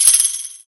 12kb - dre perc.wav